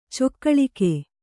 ♪ cokkaḷike